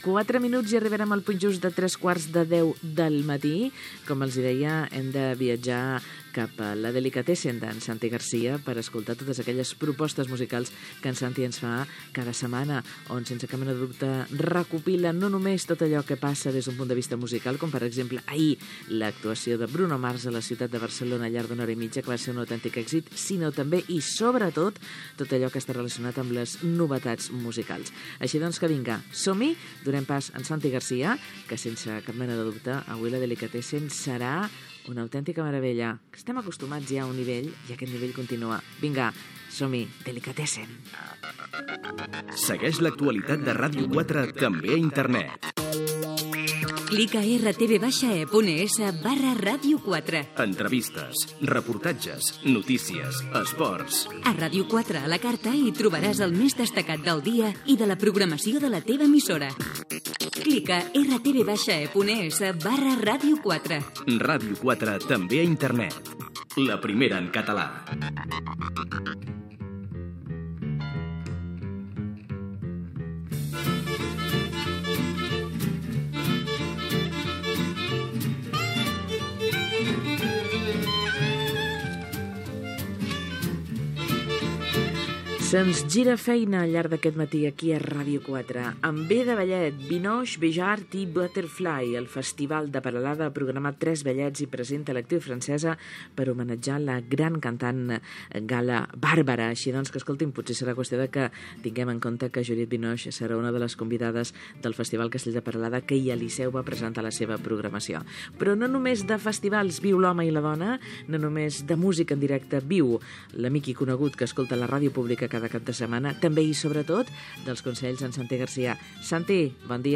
Comentari, indicatiu del programa i trucada d'una oïdora.
Entreteniment
FM